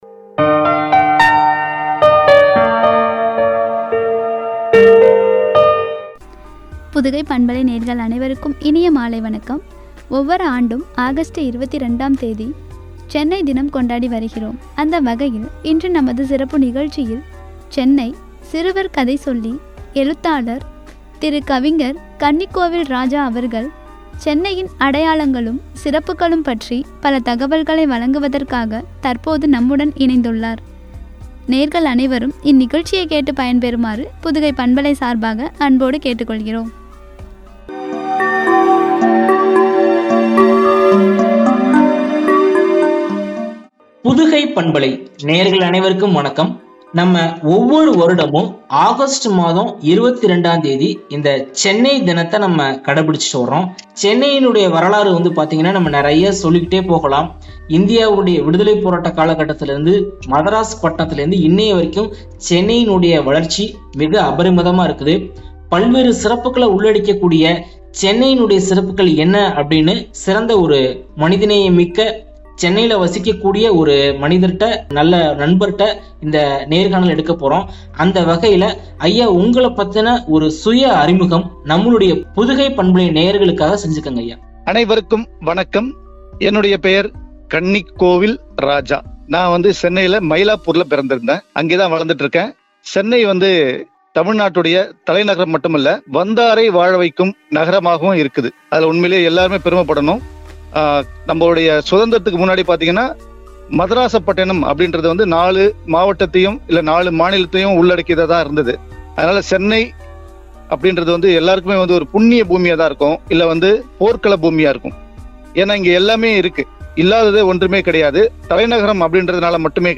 சிறப்புகளும்” குறித்து வழங்கிய உரையாடல்.